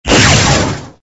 resources/phase_5/audio/sfx/lightning_3.ogg at master
lightning_3.ogg